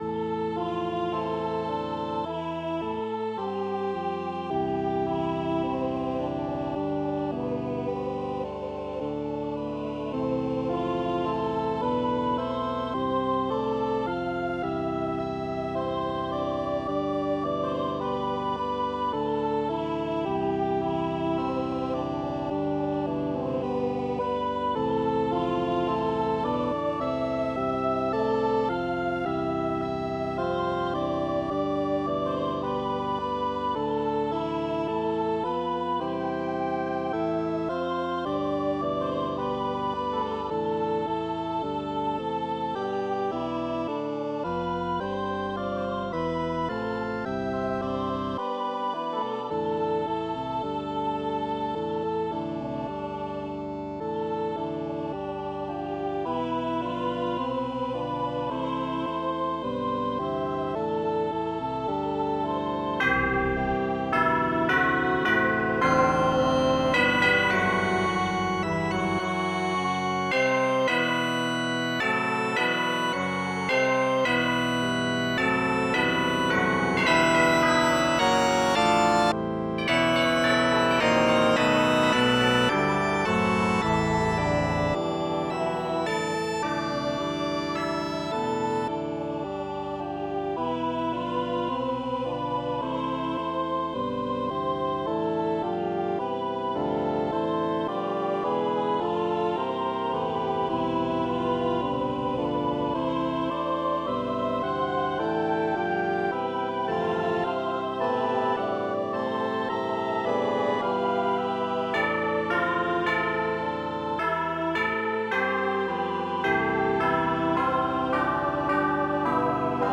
Index of /music (old)/Kurze Stücke mit Orgel und Chor 'Trümmer'